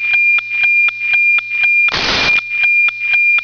гроза